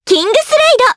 Aisha-Vox_Kingsraid_jp.wav